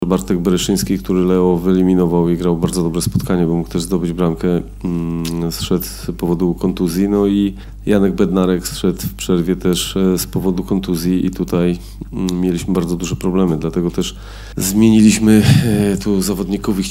Mimo to do szatni Polacy zeszli mocno osłabieni w wyniku kontuzji, o czym po starciu mówił selekcjoner reprezentacji Polski – Michał Probierz.